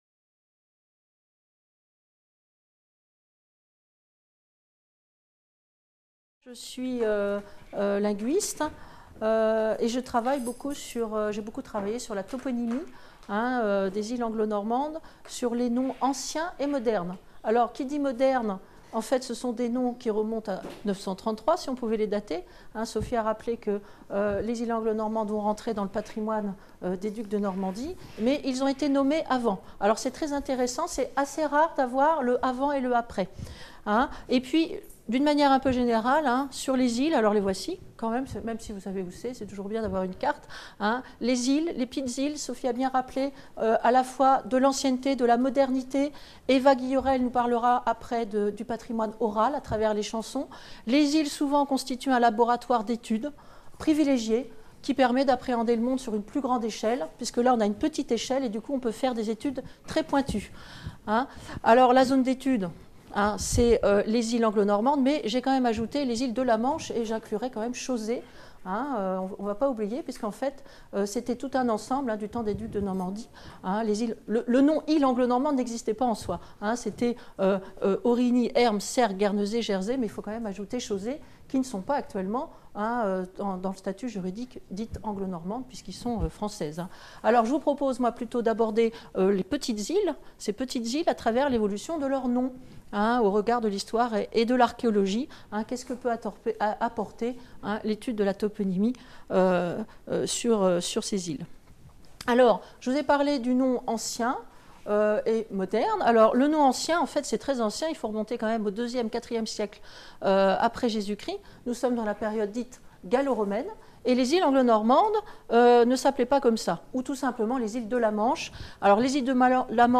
Cette communication a été filmée à Caen lors de la 3e édition de la Semaine de la mémoire, qui s'est déroulée à du 17 au 21 septembre 2018.